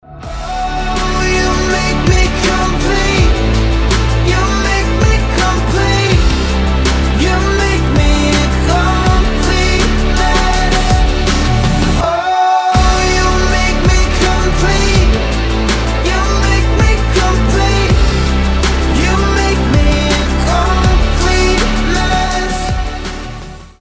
• Качество: 320, Stereo
громкие
красивый мужской голос
Pop Rock